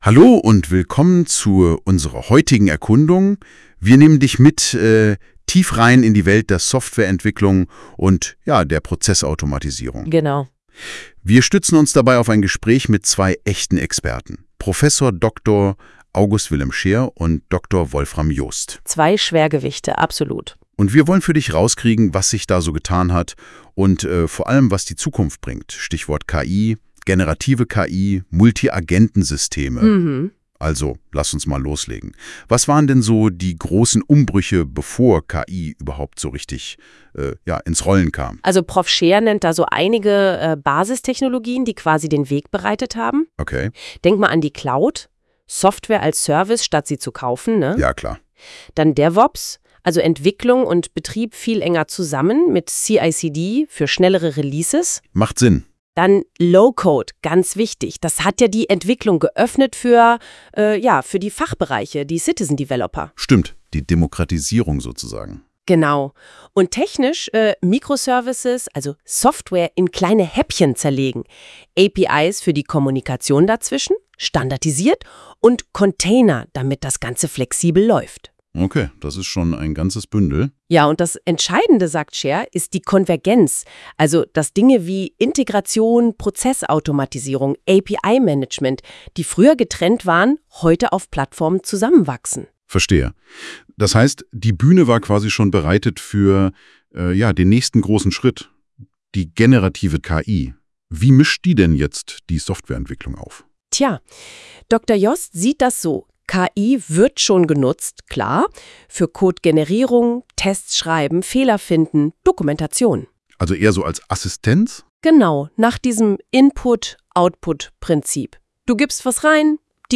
Das Interview als automatisch erstellter Podcast